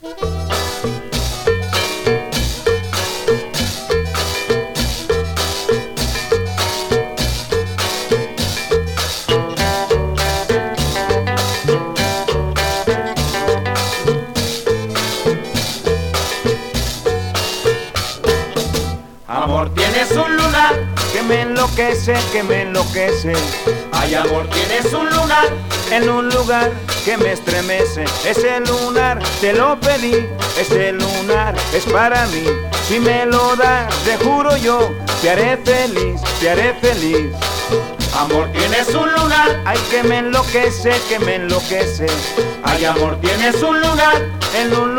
Latin, Cumbia, Boogaloo　USA　12inchレコード　33rpm　Mono/Stereo